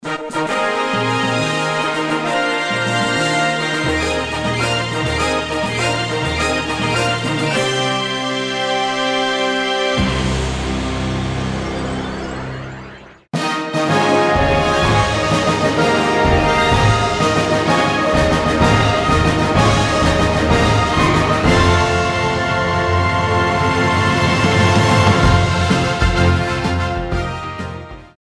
Вот на что ушла 20-ти летняя эволюция между коргом W01 и сэмплерным оркестром на трёх компах кто-нибудь понял разницу вапче?